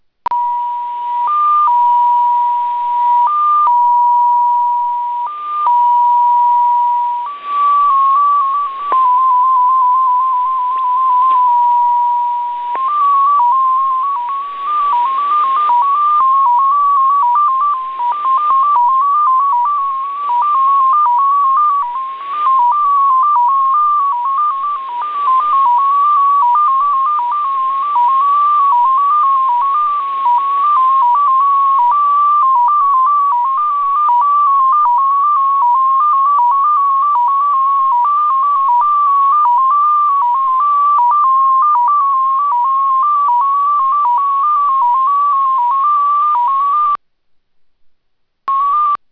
MFSK-13
Начало » Записи » Радиоcигналы классифицированные
mfsk13_start.wav